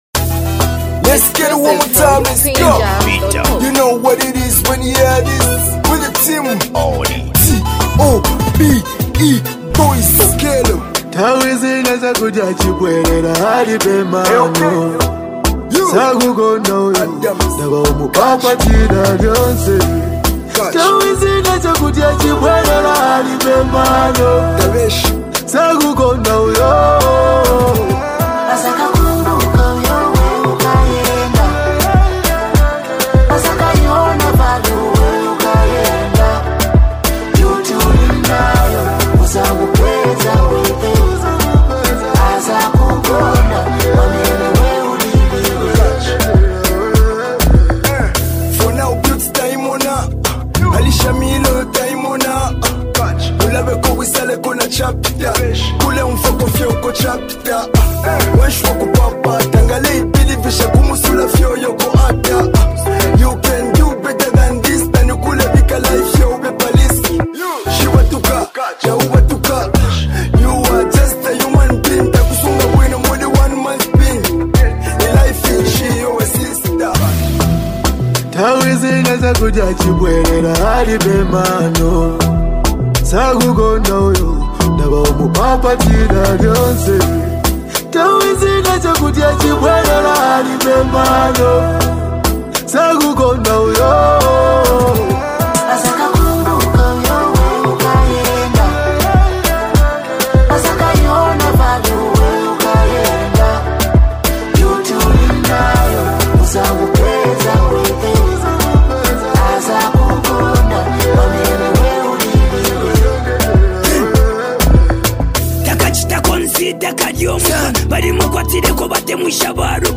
Highly multi talented act and super creative duo